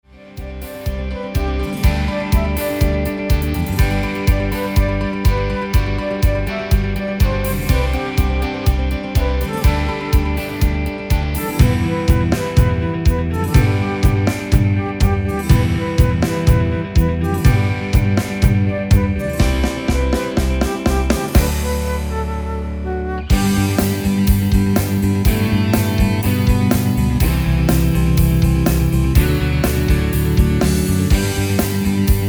Tempo: 123 BPM. hudba
Rock Czech-Slovak
MP3 with melody DEMO 30s (0.5 MB)zdarma